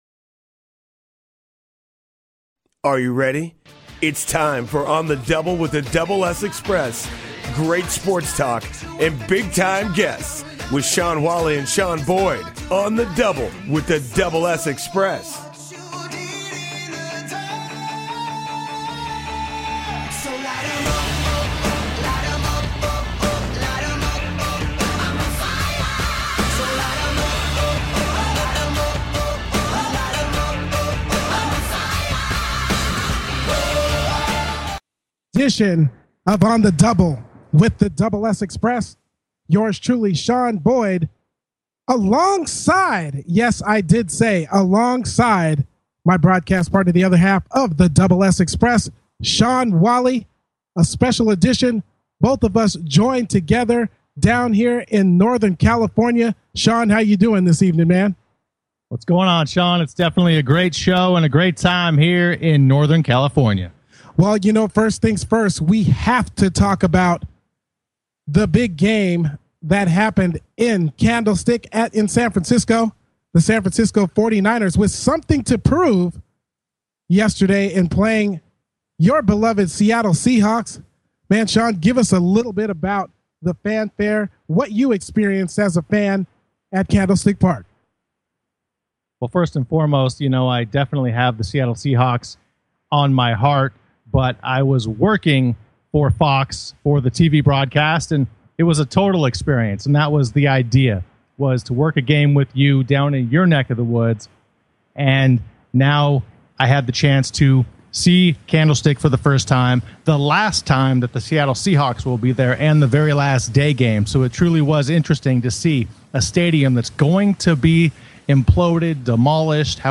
Talk Show Episode
Guest, Mack Strong